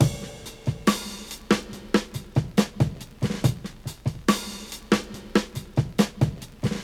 • 70 Bpm High Quality Drum Beat C Key.wav
Free drum groove - kick tuned to the C note. Loudest frequency: 1153Hz
70-bpm-high-quality-drum-beat-c-key-OlY.wav